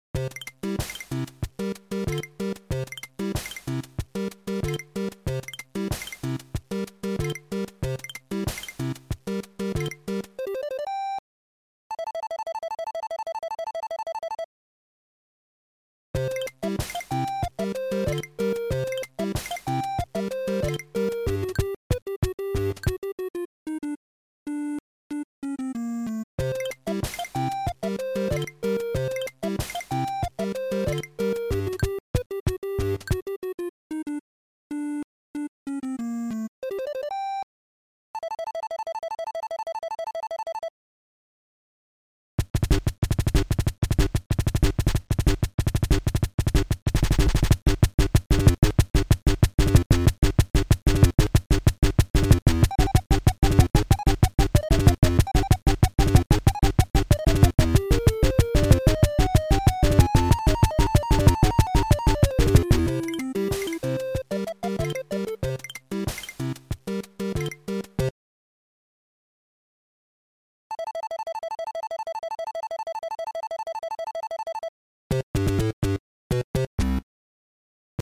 Sound Format: Noisetracker/Protracker
Sound Style: Funky